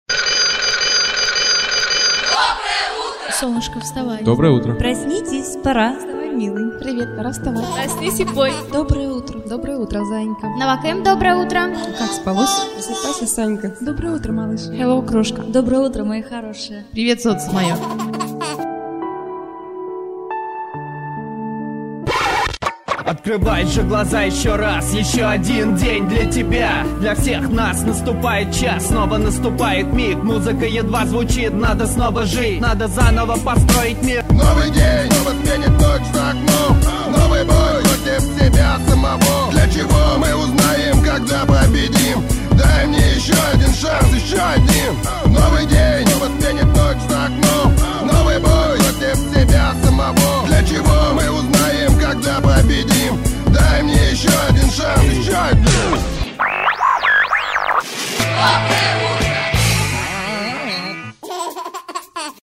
Будильник позитив (rap)